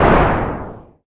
ctf_ranged_shotgun.ogg